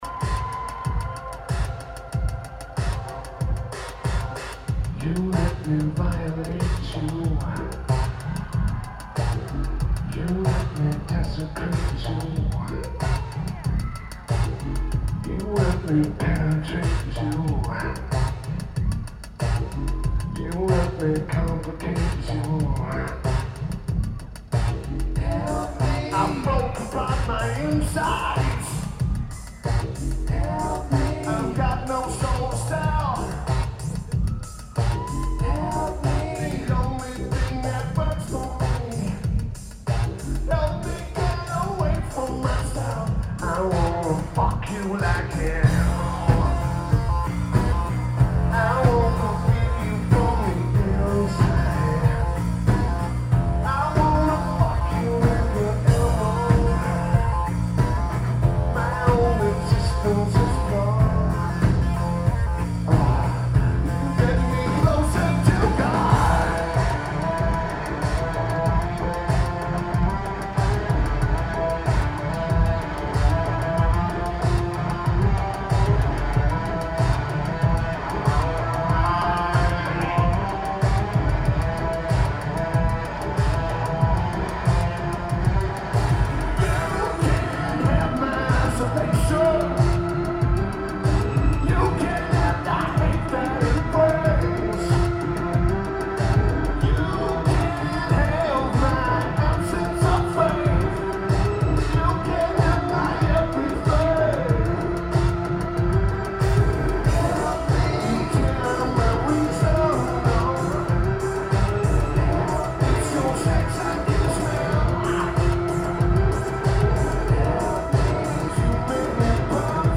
New England Dodge Music Center